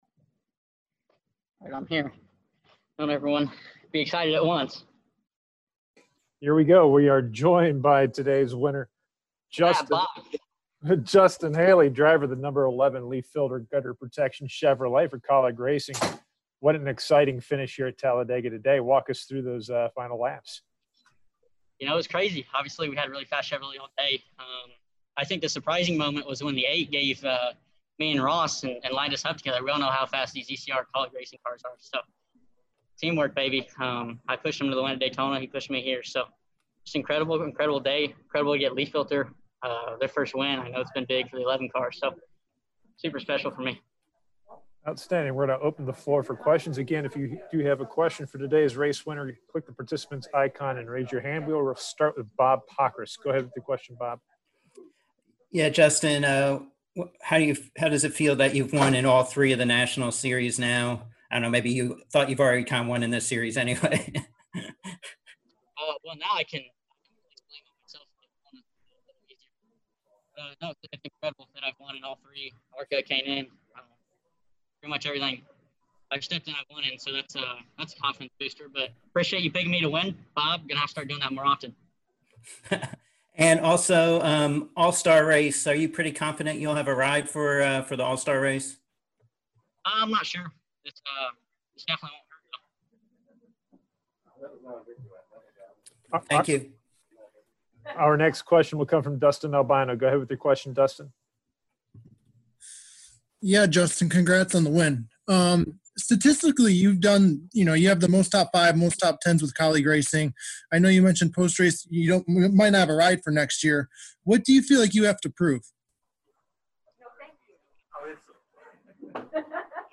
Media Center Interviews: